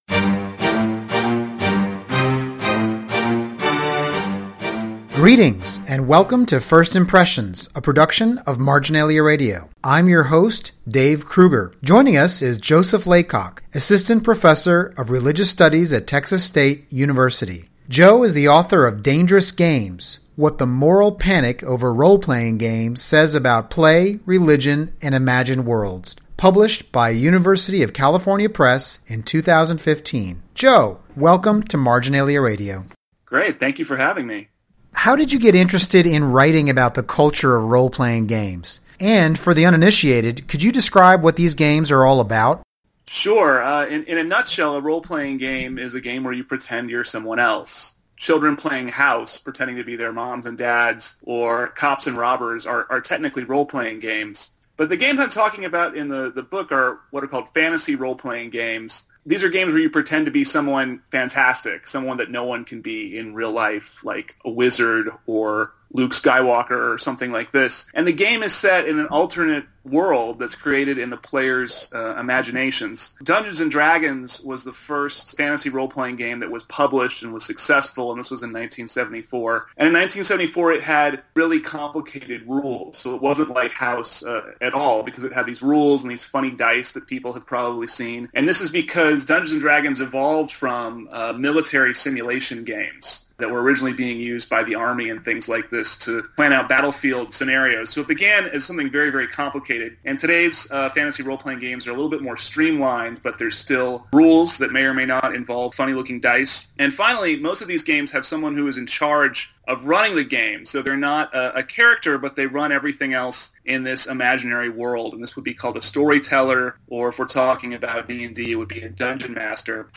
This interview was originally released as Episode #39 of MRB Radio’s First Impressions program on June 23 , 2015 . https